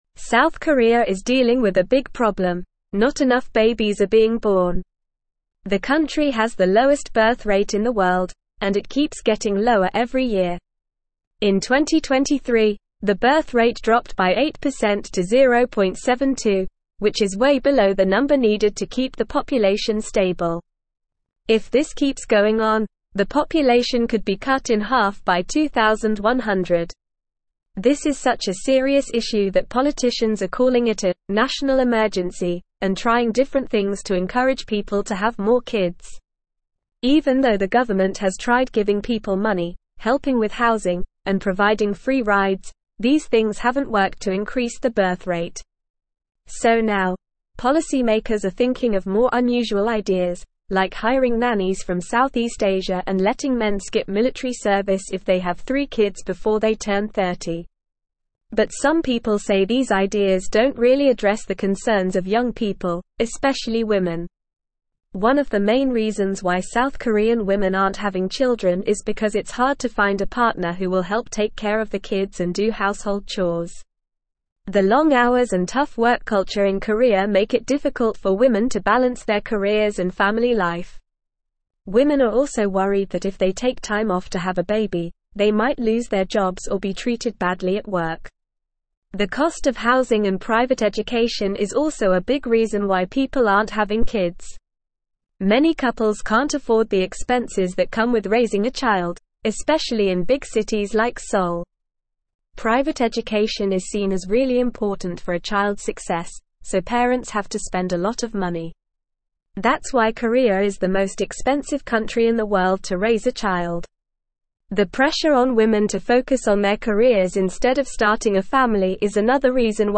Normal
English-Newsroom-Upper-Intermediate-NORMAL-Reading-South-Koreas-Birth-Rate-Crisis-Demographic-Decline-Looms.mp3